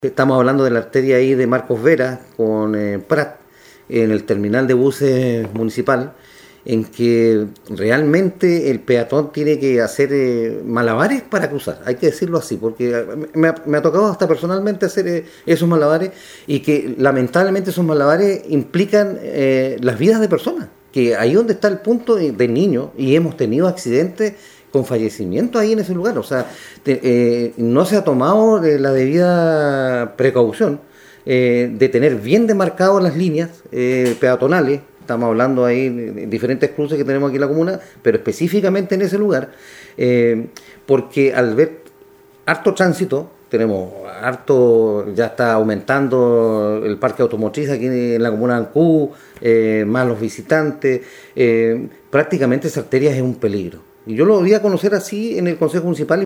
El acuerdo de Concejo hace referencia a las esquinas de Arturo Prat con Marcos Vera y Errázuriz con Los Carrera, ambas con un importante flujo vehicular y que constituyen un peligro para los transeúntes, tal cual explicó.
11-CONCEJAL-MARCOS-VELASQUEZ.mp3